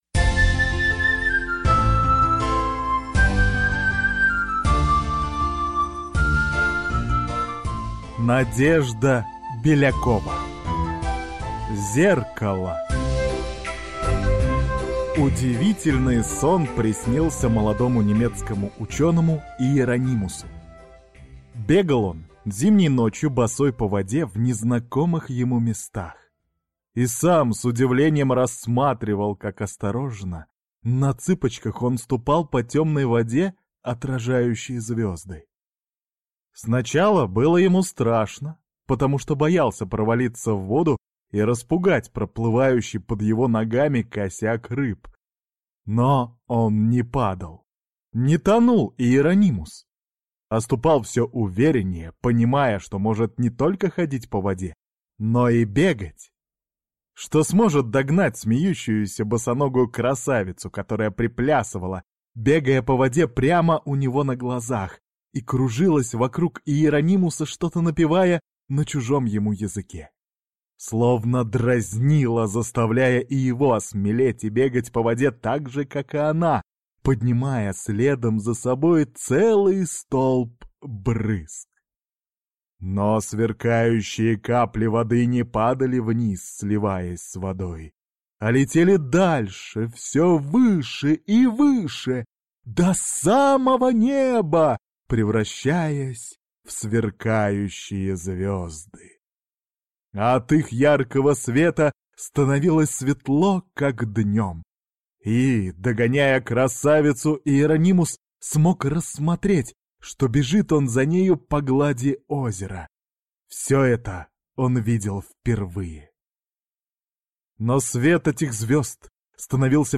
Аудиокнига Зеркало | Библиотека аудиокниг
Прослушать и бесплатно скачать фрагмент аудиокниги